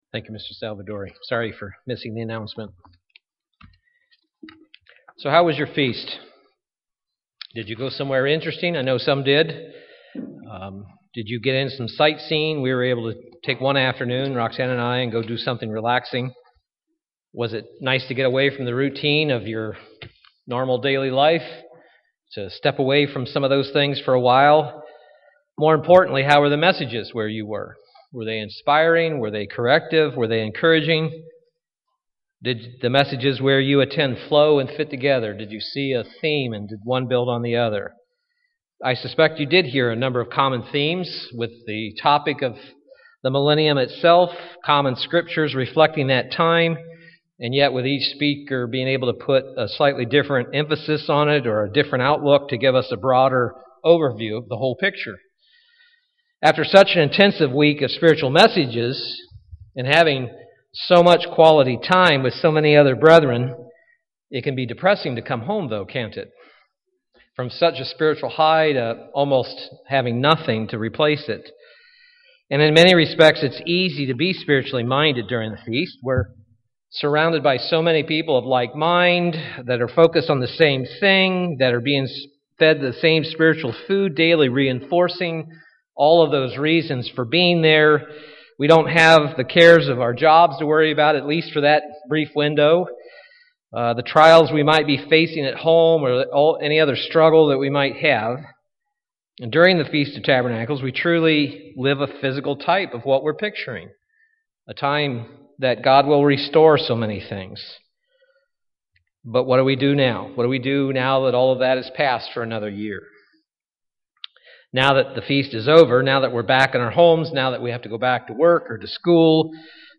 Print Drawing lessons from Nehemiah and the restoration of Jerusalem- Part 1 UCG Sermon Studying the bible?